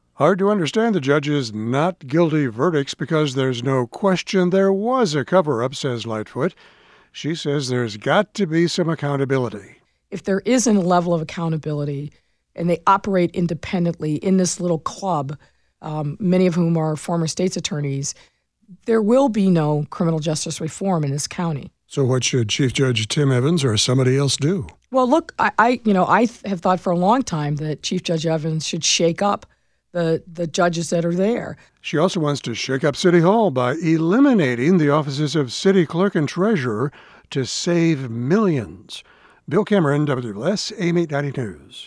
WLS-AM News